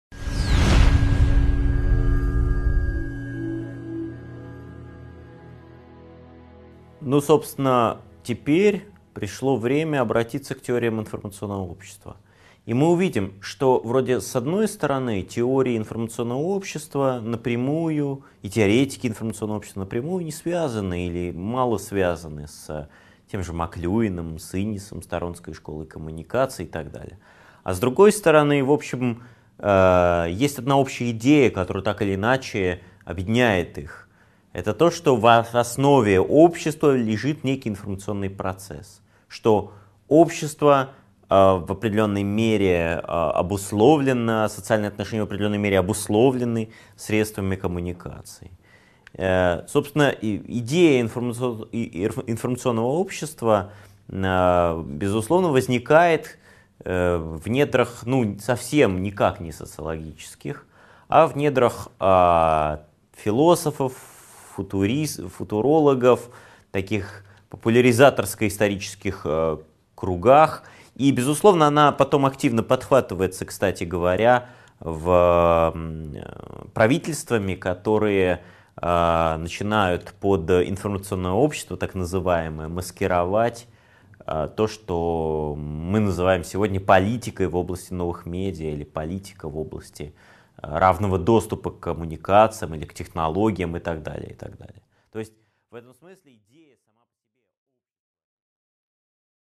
Аудиокнига 8.5 Идеи медиадетерминизма и сетевого общества: Теории информационного общества | Библиотека аудиокниг